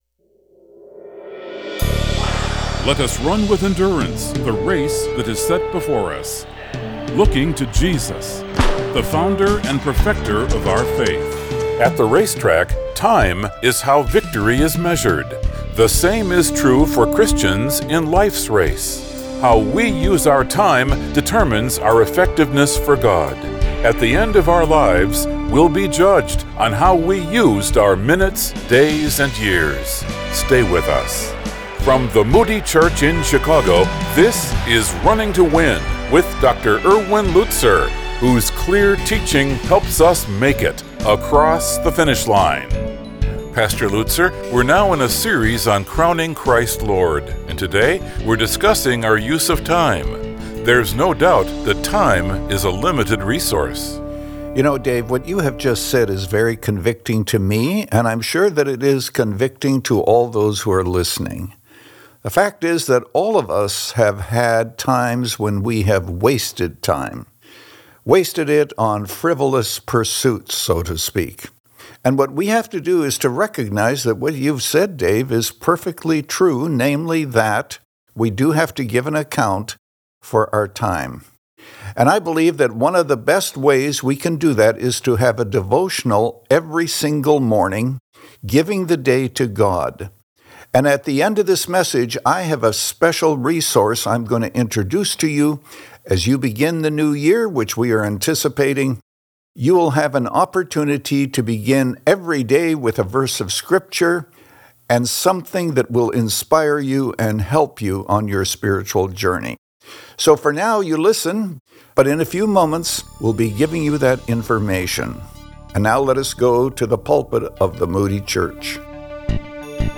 Christ, The Lord Of Our Time – Part 2 of 2 | Radio Programs | Running to Win - 25 Minutes | Moody Church Media
Since 2011, this 25-minute program has provided a Godward focus and features listeners’ questions.